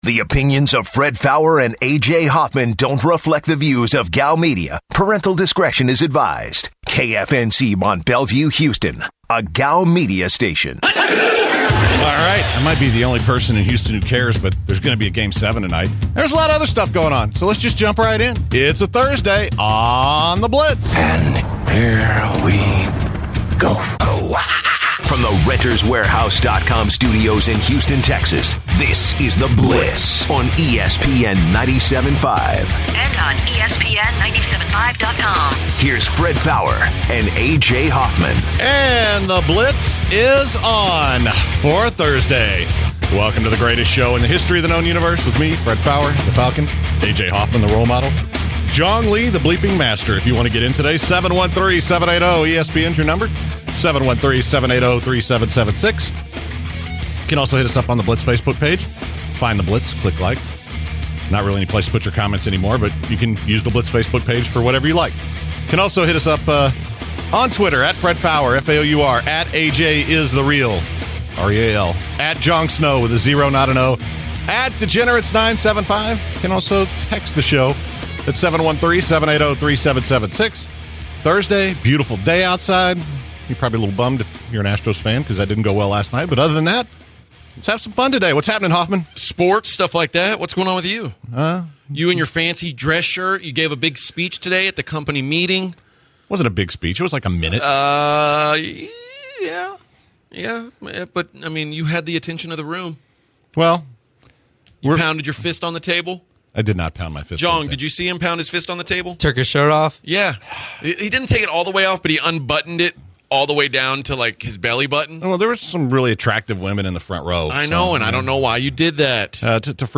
NHL discussion amongst the guys regarding the Stanley Cup Playoffs. Callers call into the show to discuss the NBA Finals, and The Houston Astros.